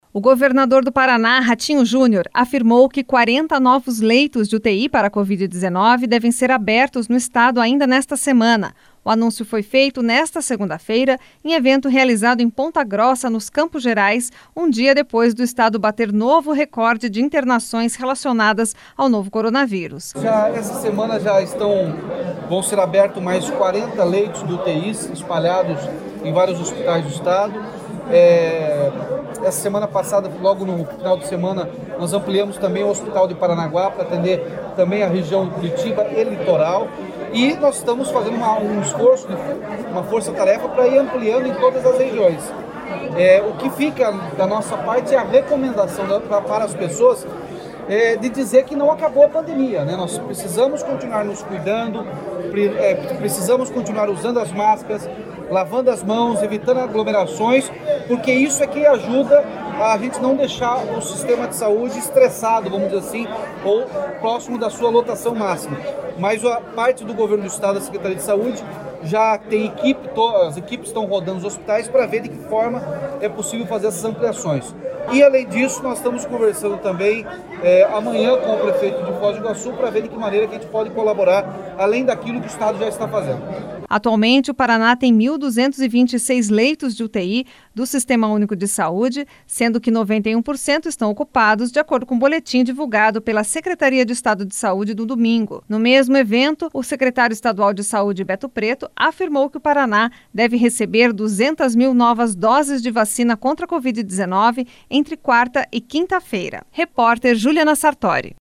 O governador do Paraná, Ratinho Junior, afirmou que 40 novos leitos de UTI para covid-19 devem ser abertos no estado ainda nesta semana. O anúncio foi feito nesta segunda-feira, em evento realizado em Ponta Grossa, nos Campos Gerais, um dia depois do estado bater novo recorde de internações relacionadas com o novo coronavírus.